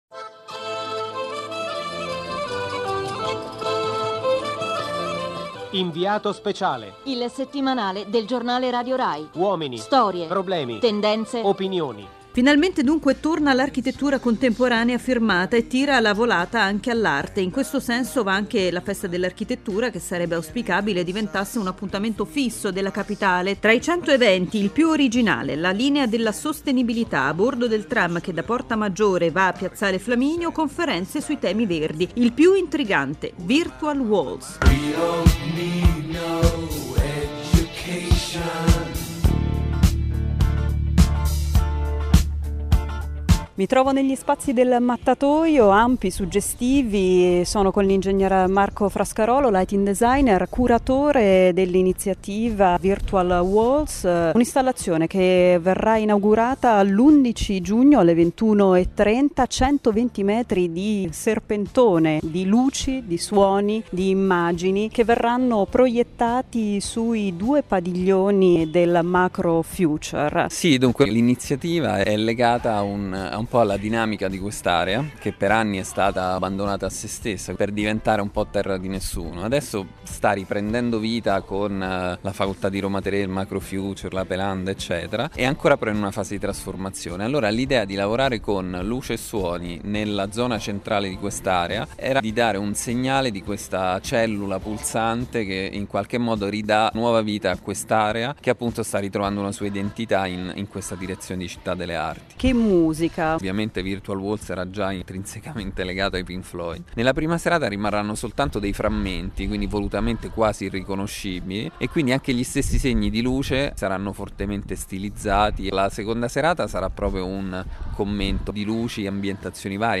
Virtual Walls – Intervista